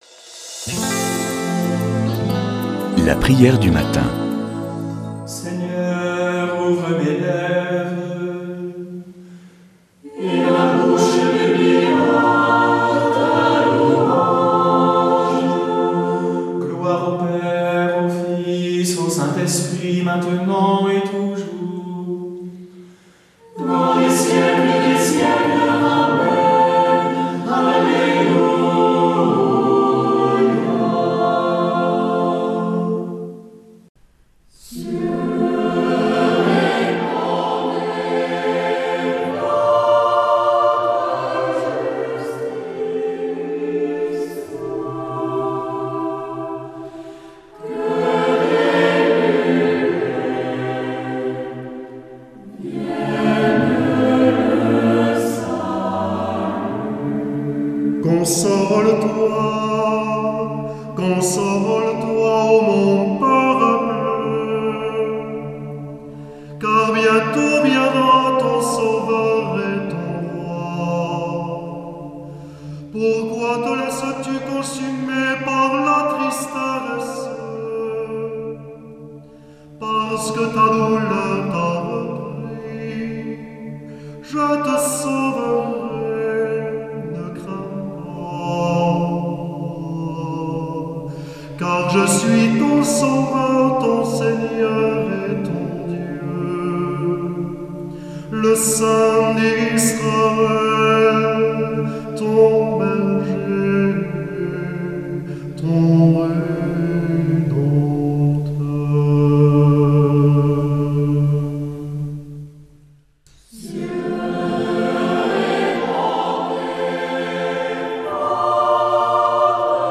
Prière du matin